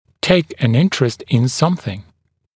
[teɪk ən ‘ɪntrəst ɪn ‘sʌmθɪŋ][тэйк эн ‘интрэст ин ‘самсин]интересоваться ч.-л.